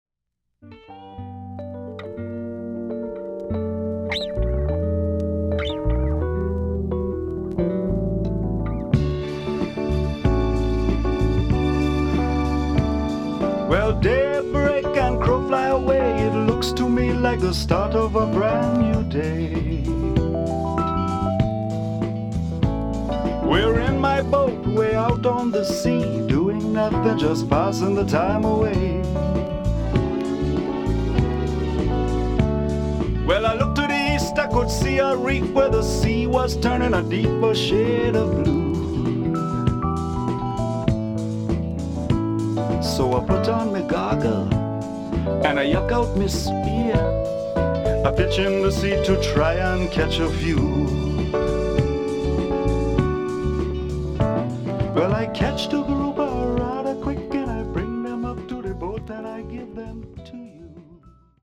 Chilled early 80's Island vibes